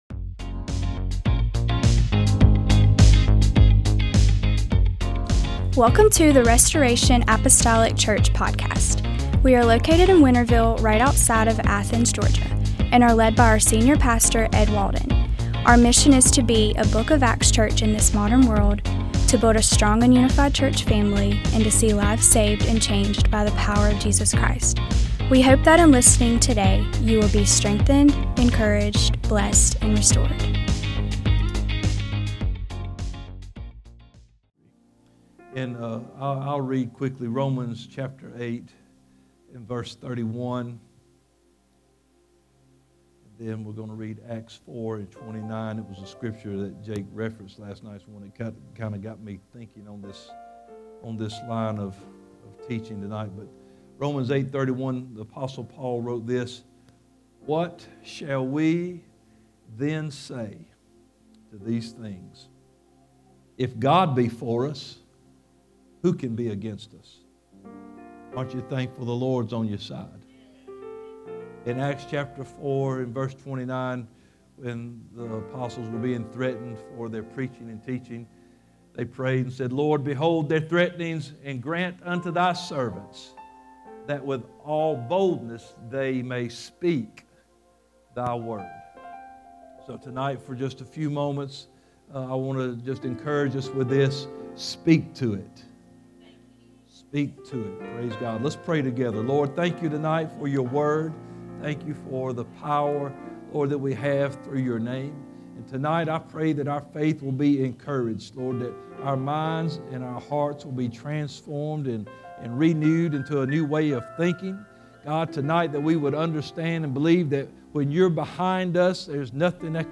MDWK Revival